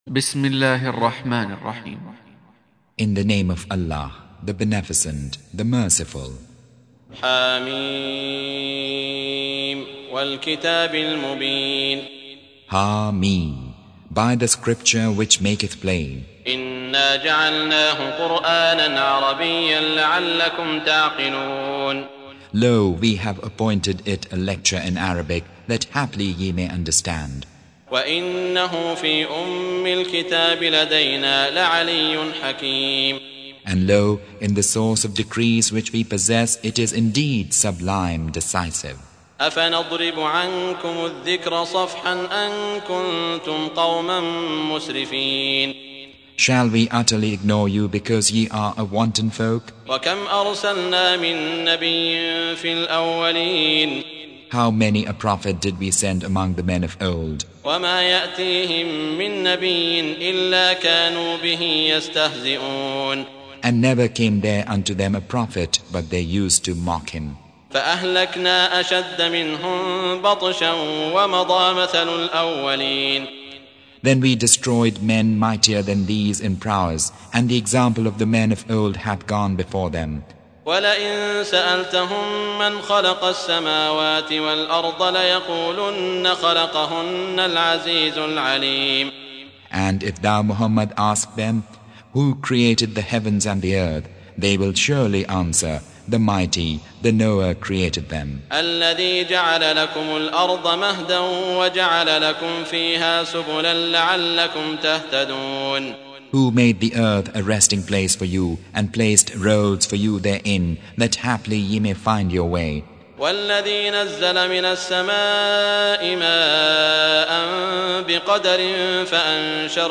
Surah Repeating تكرار السورة Download Surah حمّل السورة Reciting Mutarjamah Translation Audio for 43.